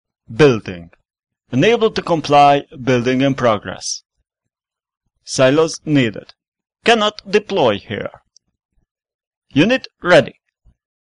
I decided that you'll be the Nod EVA since you said one time you voice is too deep.
Never played FF but I guess that's either because VA is either twice as old as me (I'm still 22) and/or further deeping in effects (lowering the pitch with max ~12% ain't give it an unnatural feeling but still is deeper).